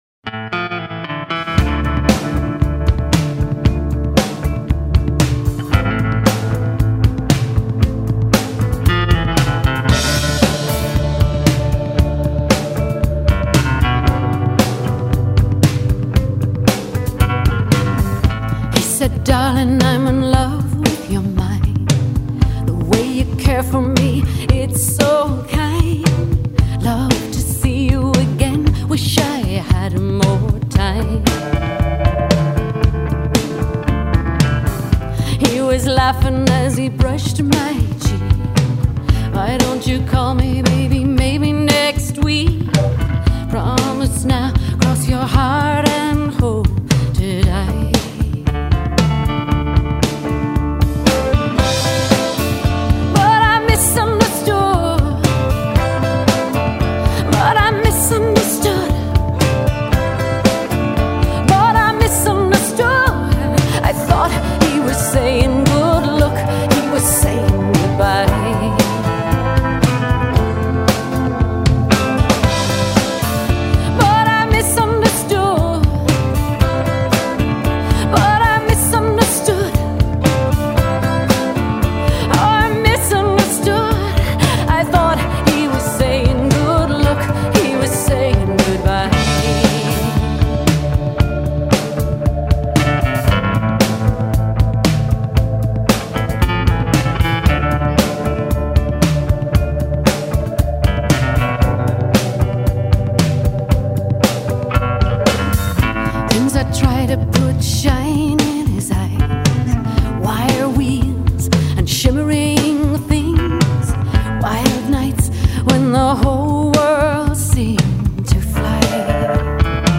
《电报》称她的嗓音“祥和又有带着些许伤感的优美”。
1996年末，这张唱片在洛杉矶完成录制。